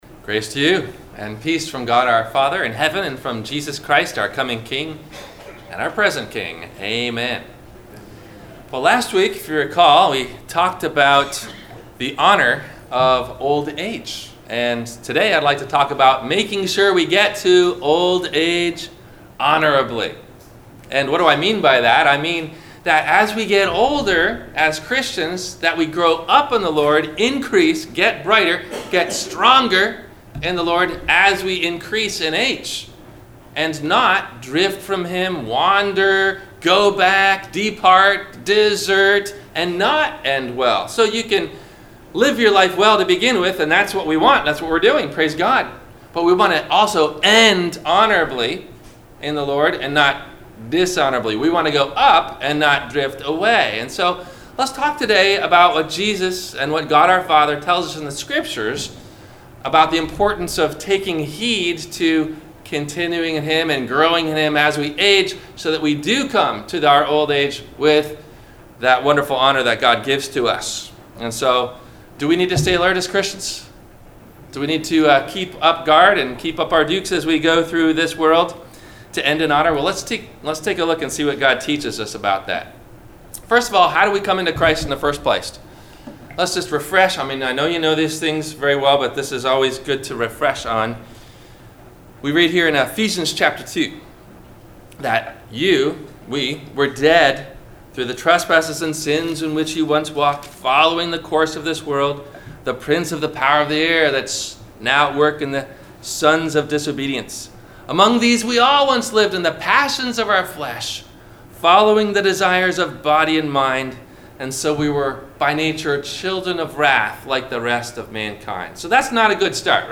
- Sermon - February 03 2019 - Christ Lutheran Cape Canaveral